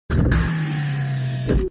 door close.wav